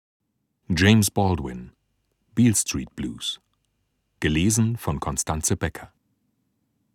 Beale Street Blues Ungekürzte Lesung
Constanze Becker (Sprecher)